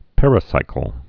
(pĕrĭ-sīkəl)